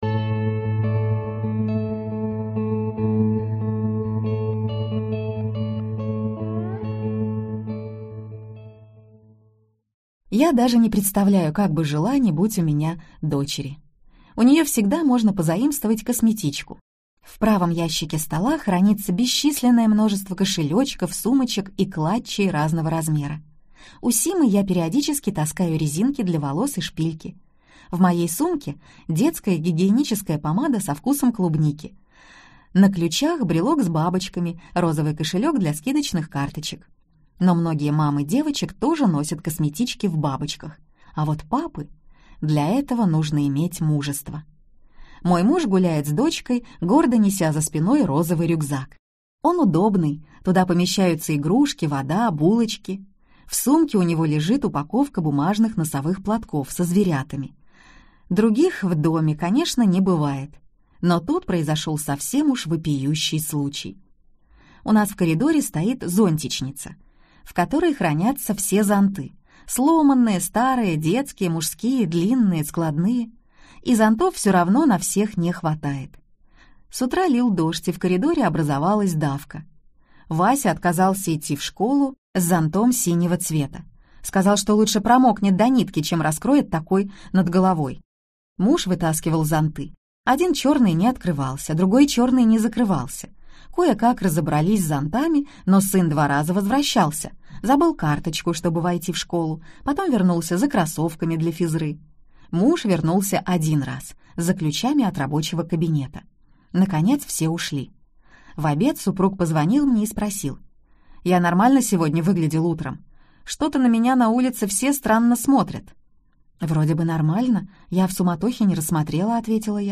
Аудиокнига Миллиграммы счастья - купить, скачать и слушать онлайн | КнигоПоиск